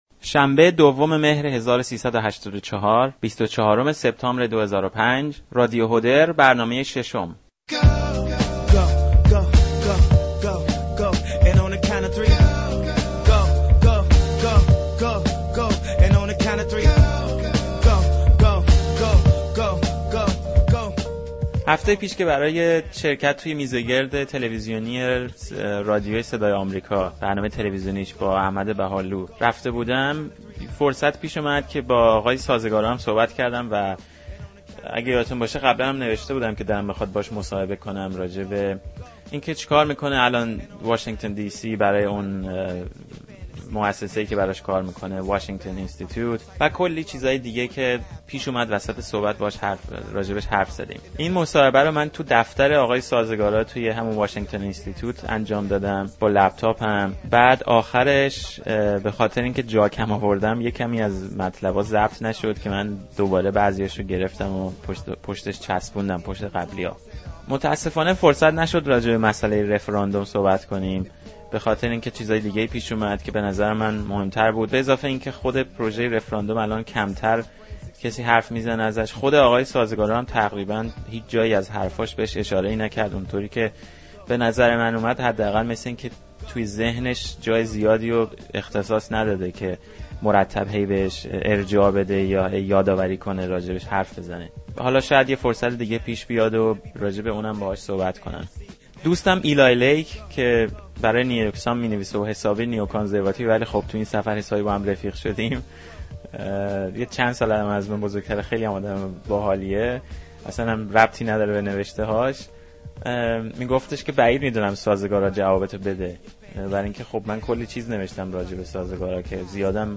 محسن سازگارا - گفتگوی حسين درخشان با محسن سازگارا